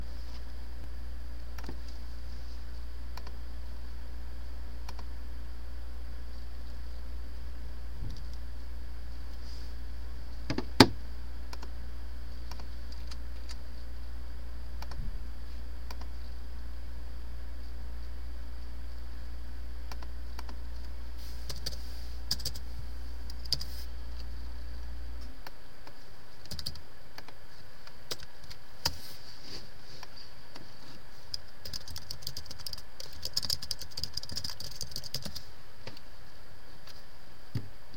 Field Recording 7
SOUND CLIP: Republic Hall Room 211 LOCATION: Republic Hall Room 211 SOUNDS HEARD: hum from refrigerator, typing on keyboard, mouse clicking, sniffling, putting cell phone down